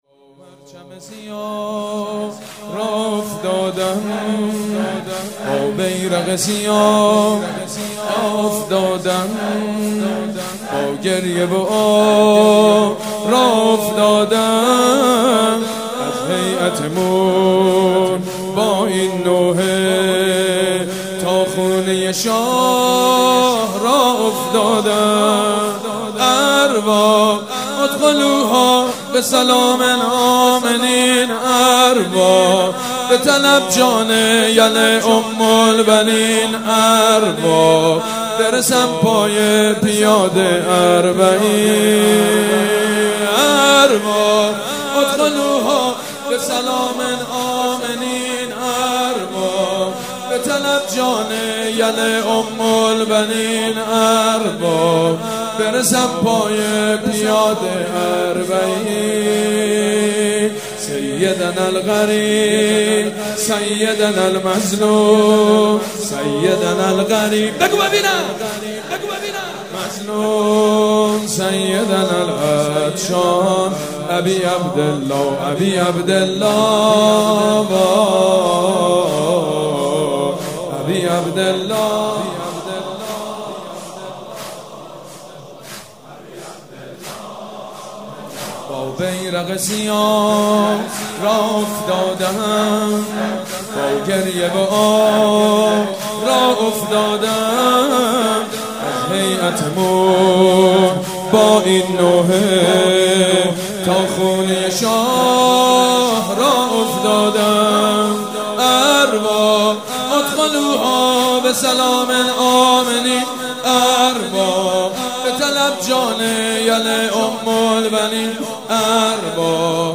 مداحی زیبای حاج سید مجید بنی فاطمه در شب دوم محرم در فضای مجازی انتشار یافت.